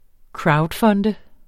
Udtale [ ˈkɹɑwdˌfʌndə ]